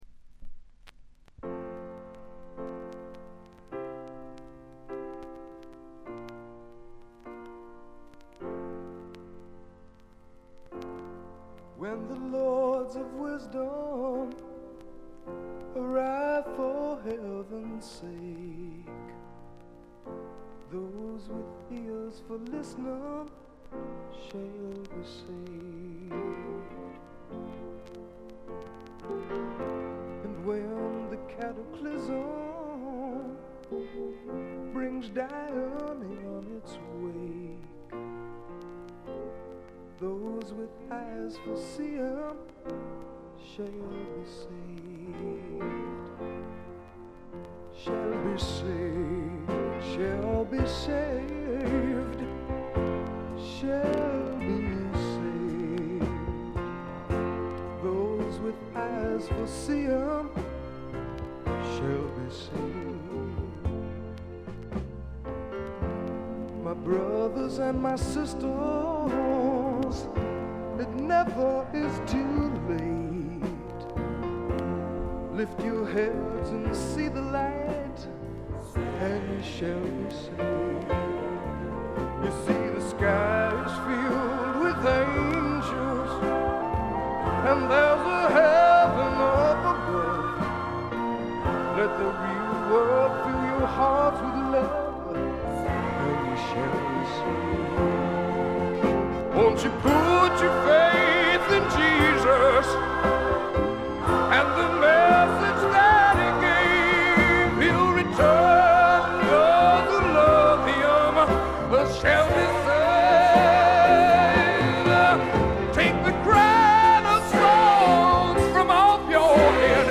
特別に目立つノイズはありません。
西海岸製スワンプ系シンガーソングライターの裏名盤です。
試聴曲は現品からの取り込み音源です。